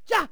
valkyrie_attack5.wav